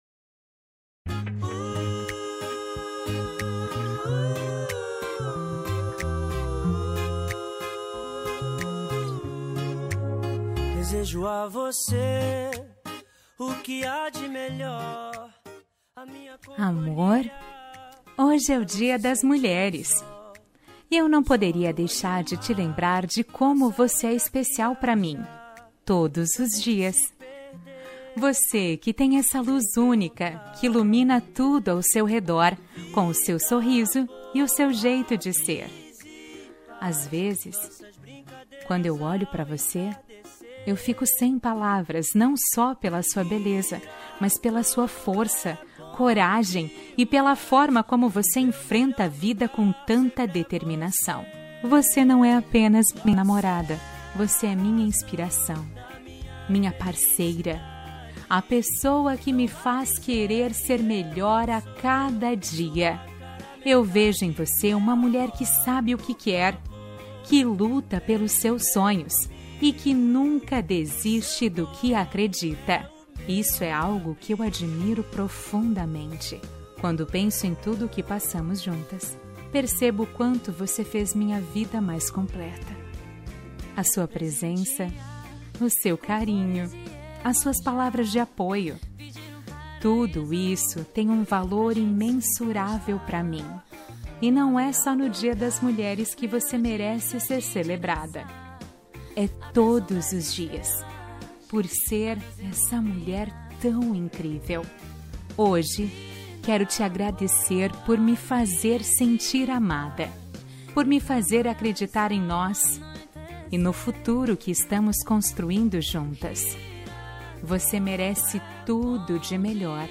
Dia das Mulheres – Namorada – Voz Feminina – Cód: 690713